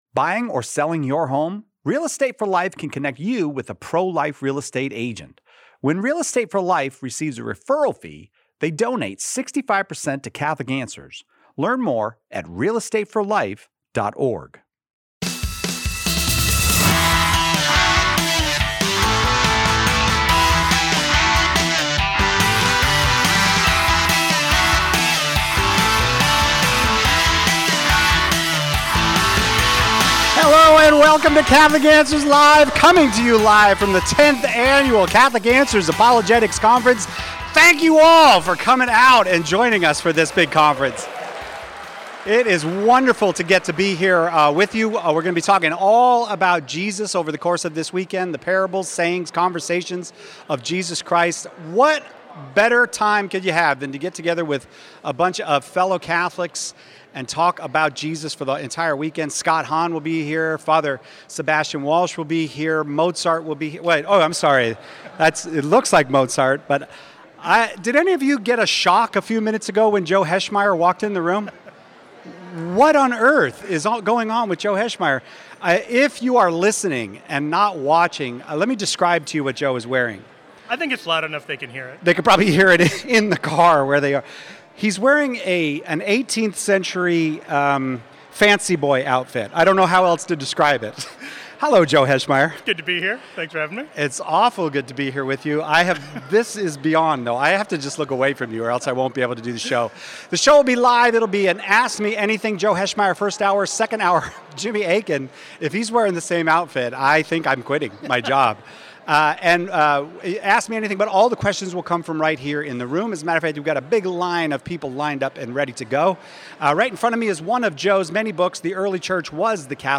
Live from the Catholic Answers Conference